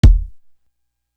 Still Feel Me Kick.wav